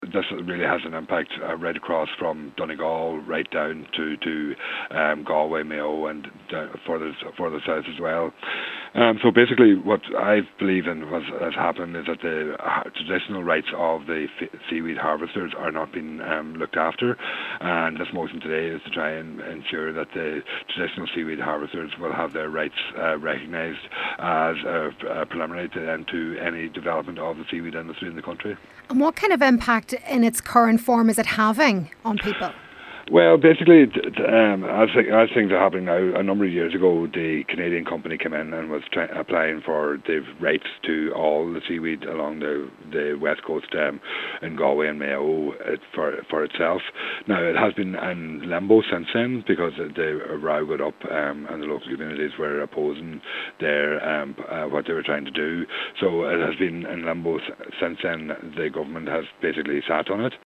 He says the Government need to initiate sufficient regulations to protect local people: